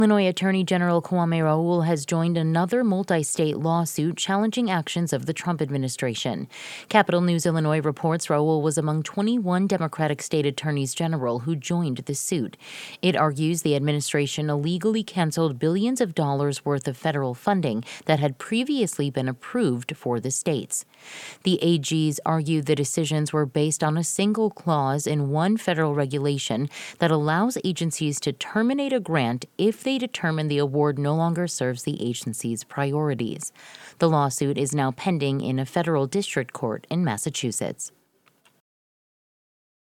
Speaking Monday to a congressional panel made up of Democratic members of the U.S. House and Senate judiciary committees, Raoul joined three other members of that coalition to explain their litigation campaign.